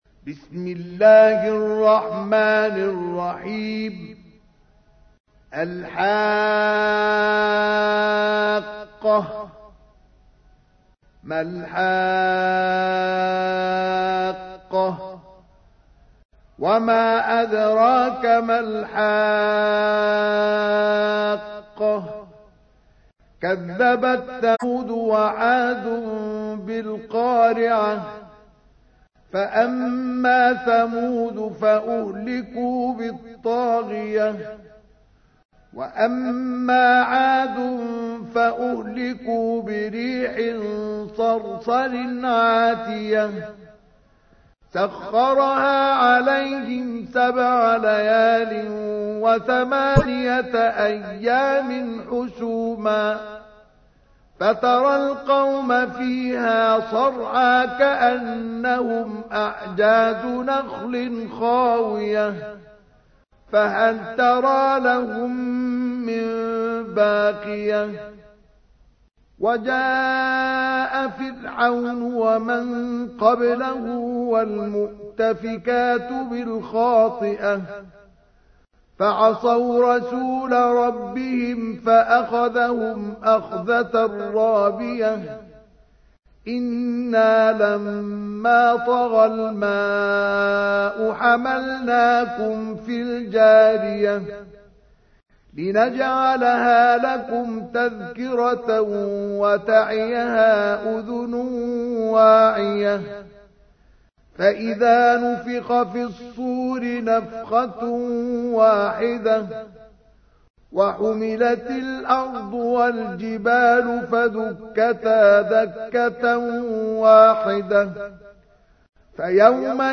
تحميل : 69. سورة الحاقة / القارئ مصطفى اسماعيل / القرآن الكريم / موقع يا حسين